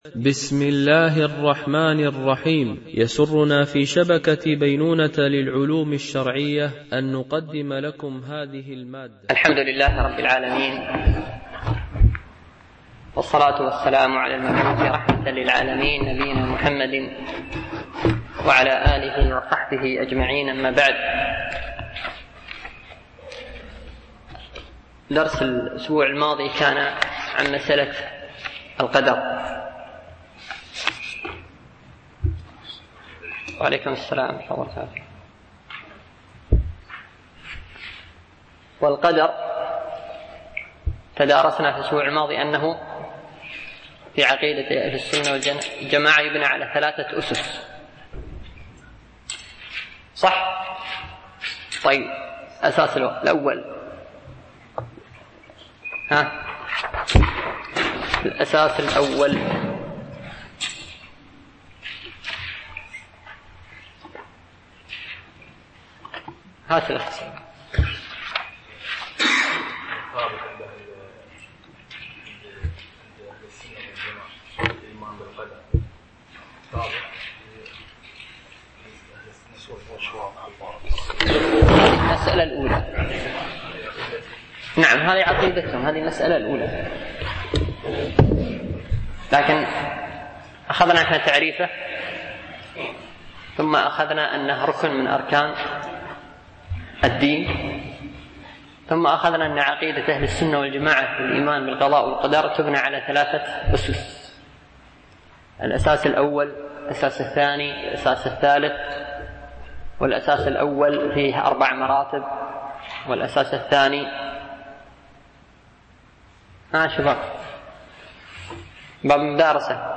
شرح المقدمة العقدية لكتاب الرسالة لابن أبي زيد القيرواني - الدرس 06